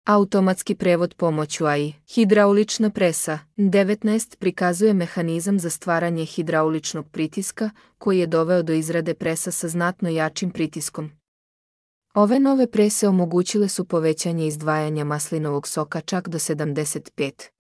Аудио водич / Audio vodič.